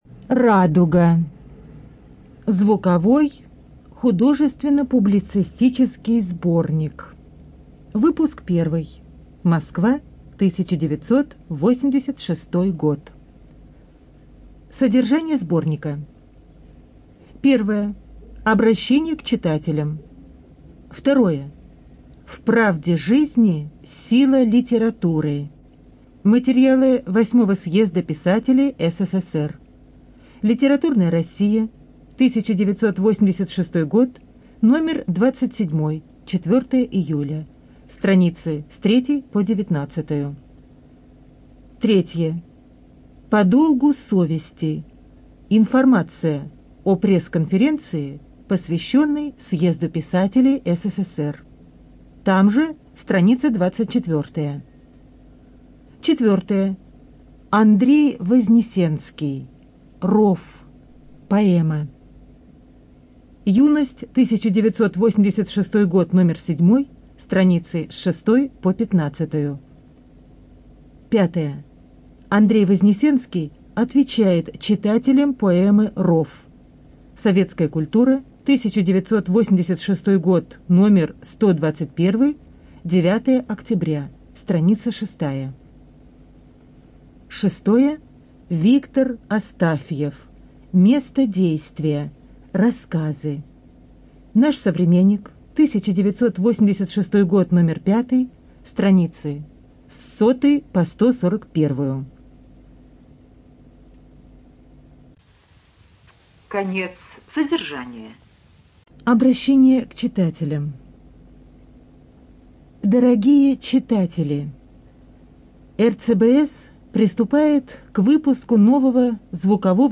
Студия звукозаписиРоссийская государственная библиотека для слепых